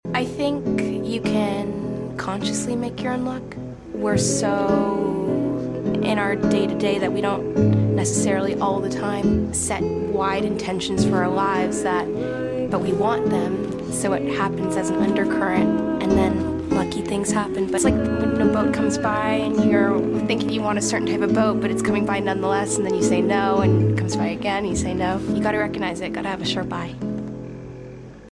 asmr routine